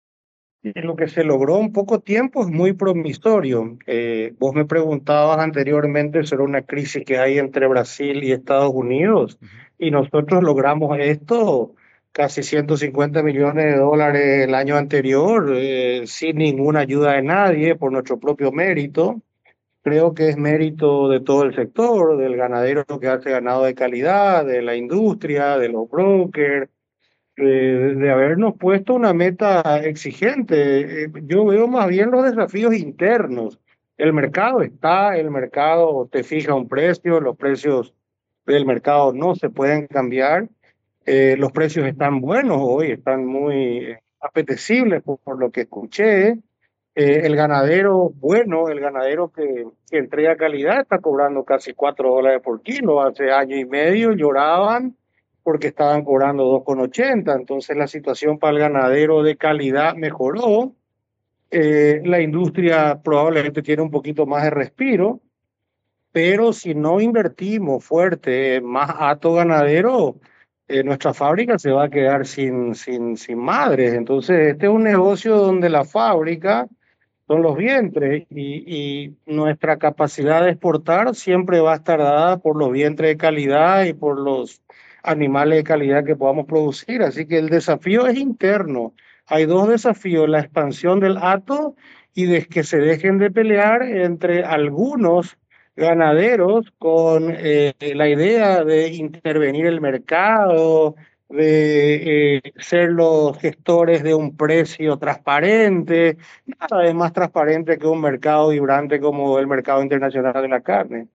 En un momento de crecimiento y oportunidades en el mercado internacional de la carne, y desafíos internos para seguir potenciando la producción; el embajador de Paraguay en Estados Unidos, Gustavo Leite, conversó con Valor Agregado en Radio Asunción 1250 AM y remarcó puntos, que consideró clave, para el desarrollo pecuario del país.